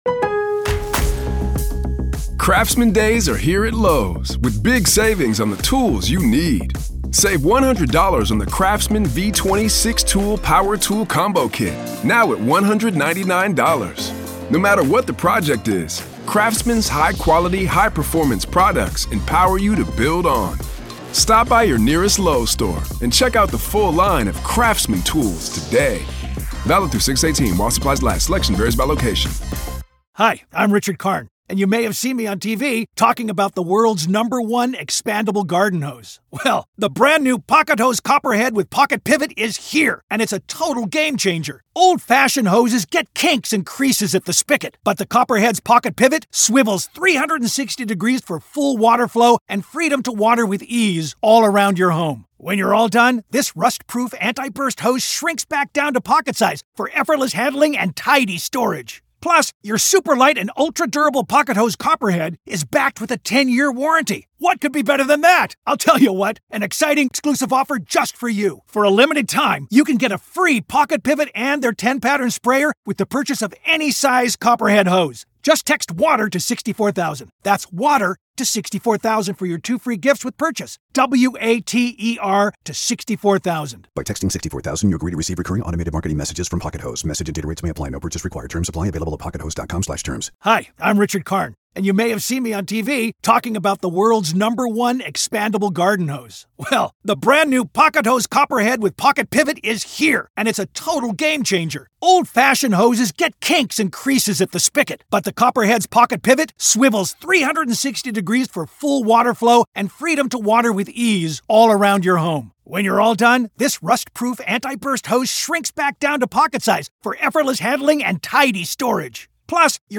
NM v. Alec Baldwin Manslaughter Trial-DAY 1 Part 4